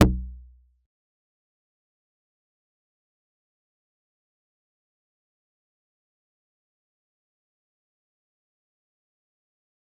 G_Kalimba-E1-pp.wav